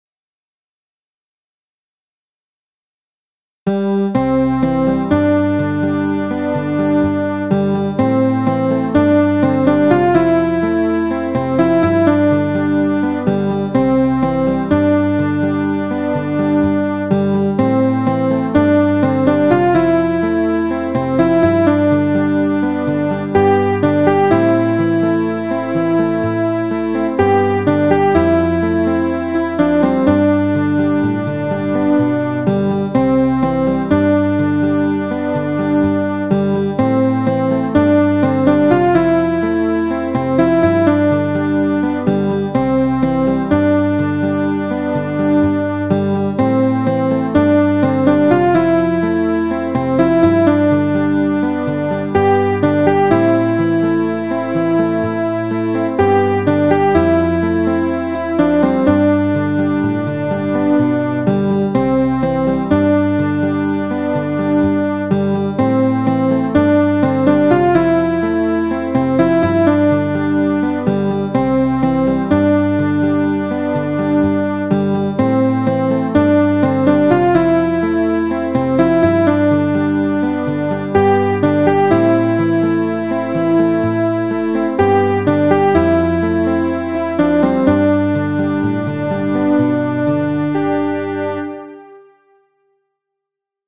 A contemplative Psalm and allegory.